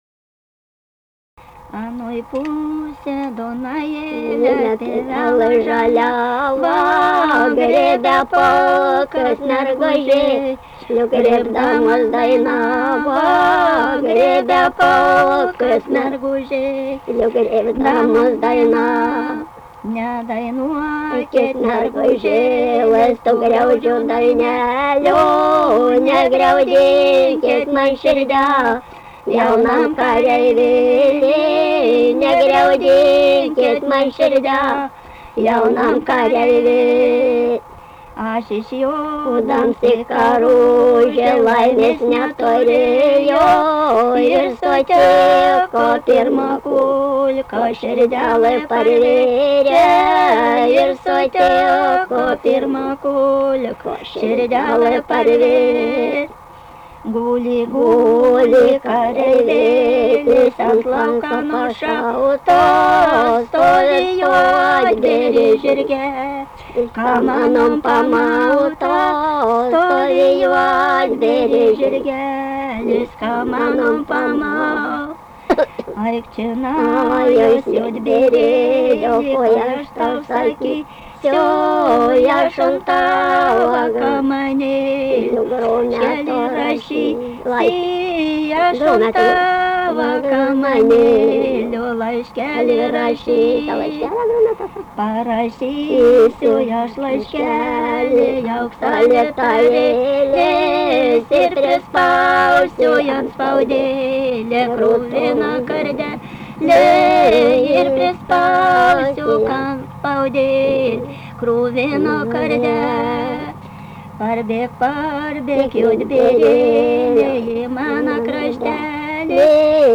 daina, karinė-istorinė
Jakūboniai
vokalinis